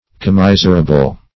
Commiserable \Com*mis"er*a*ble\, a.